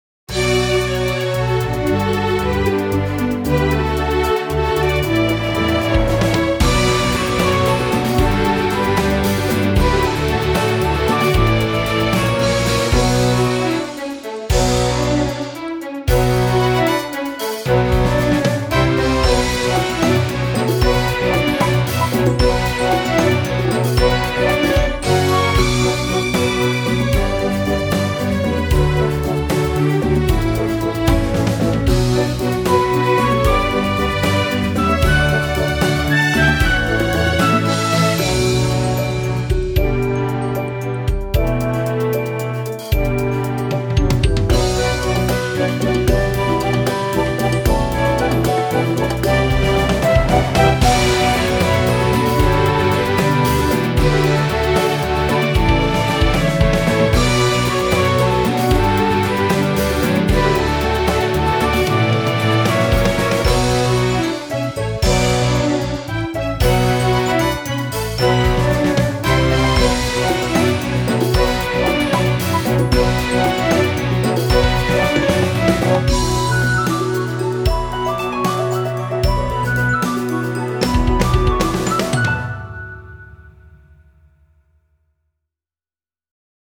ファンタジックな戦闘曲です。壮大な感じの民族調の音楽がほしいときにどうぞ。ループしません。